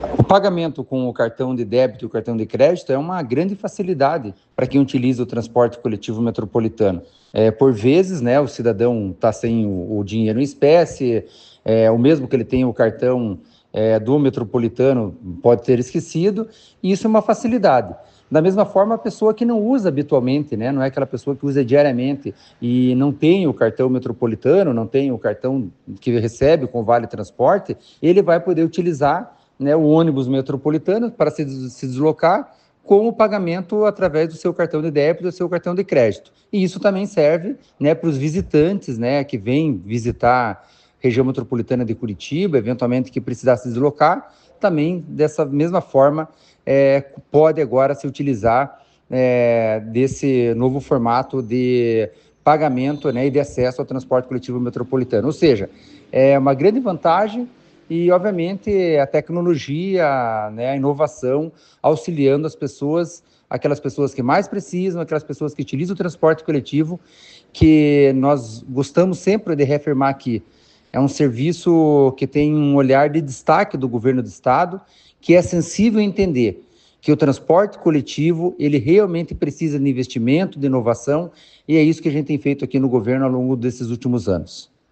Sonora do diretor-presidente da Amep, Gilson Santos, sobre a implantação do pagamento por aproximação nos ônibus metropolitanos